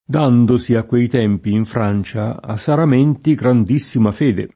saramento [Saram%nto] s. m. — francesismo ant. per sacramento nel senso di «giuramento»: dandosi a quei tempi in Francia a’ saramenti grandissima fede [
d#ndoSi a kkUei t$mpi in fr#n©a a Saram%nti grand&SSima f%de] (Boccaccio)